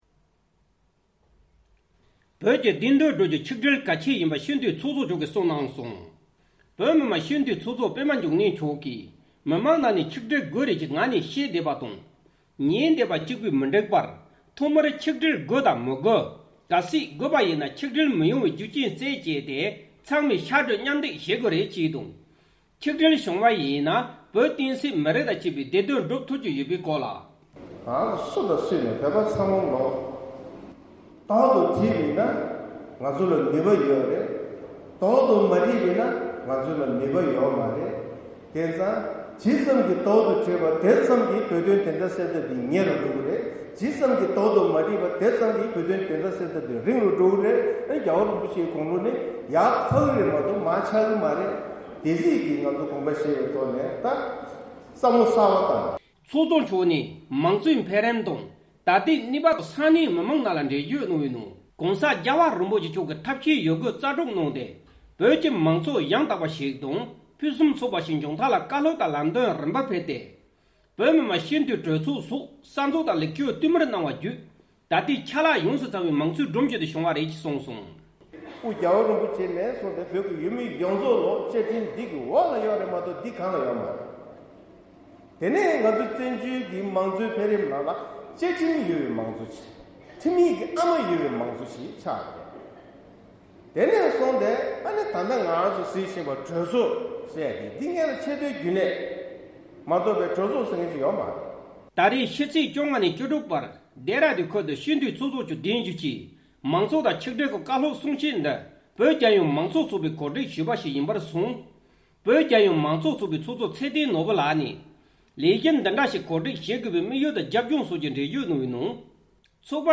བོད་ཀྱི་བདེན་དོན་བསྒྲུབ་རྒྱུར་ཆིག་སྒྲིལ་གལ་ཆེ་ཡིན། བོད་མི་མང་སྤྱི་འཐུས་ཚོགས་གཙོ་པདྨ་འབྱུང་གནས་ལགས་ཀྱིས་ལྡེ་ར་ལྡུན་ཁུལ་གྱི་བོད་མི་ཚོར་གསུང་བཤད་གནང་བ།
སྒྲ་ལྡན་གསར་འགྱུར།